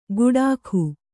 ♪ guḍākhu